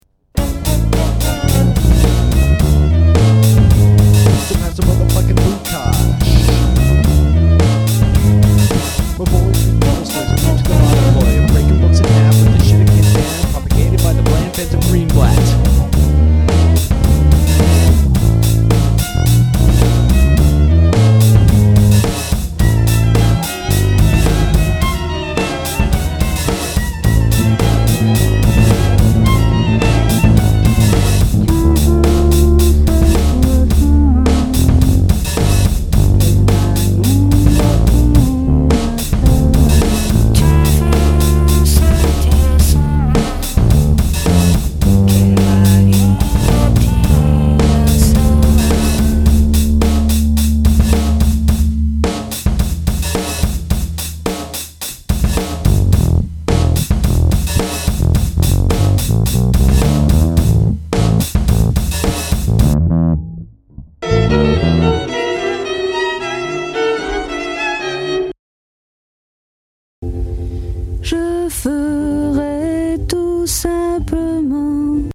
Rap
with bass and beats